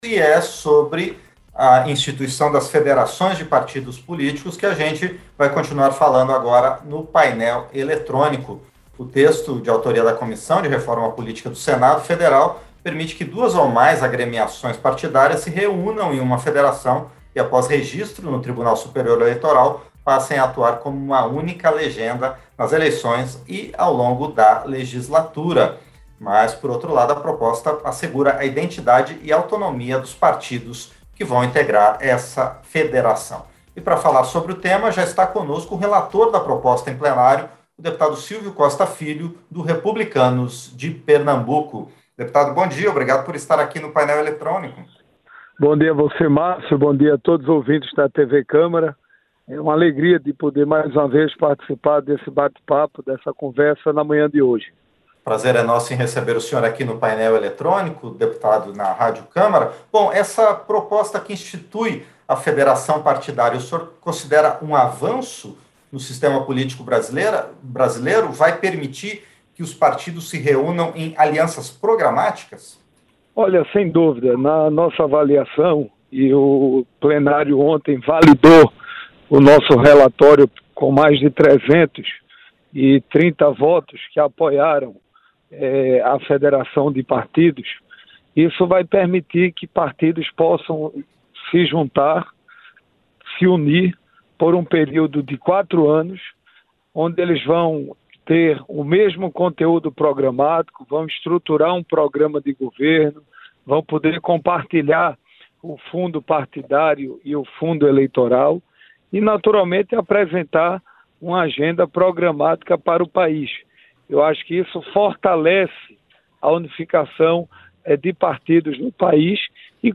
• Entrevista - Dep. Silvio Costa Filho (Republicanos-PE)
Programa ao vivo com reportagens, entrevistas sobre temas relacionados à Câmara dos Deputados, e o que vai ser destaque durante a semana.